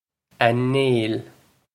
A Néill Ah Nyale
Ah Nyale
This is an approximate phonetic pronunciation of the phrase.